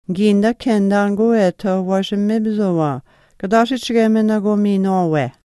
geese_08.mp3